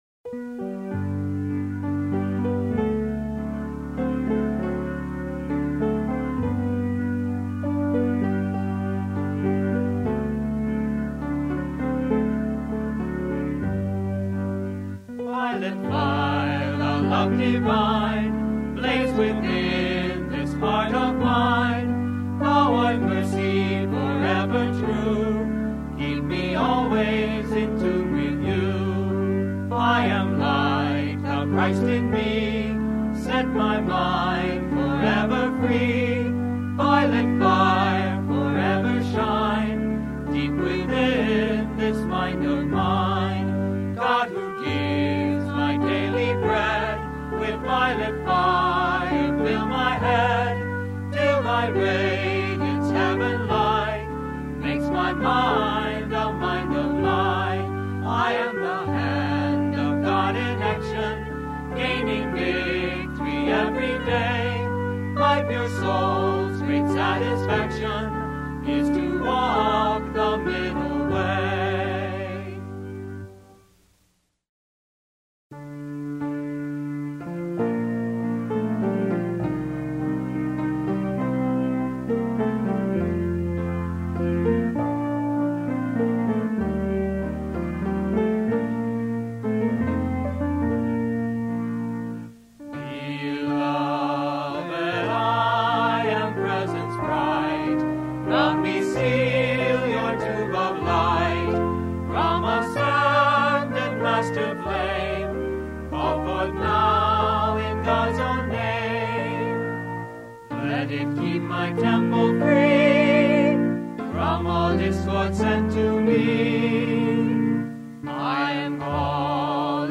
Decretos de Corazón, Cabeza y Mano (Cantado)
Heart, Head, and Hand Decrees (Song)
Heart-Head-and-Hand-Decrees-song-complete.mp3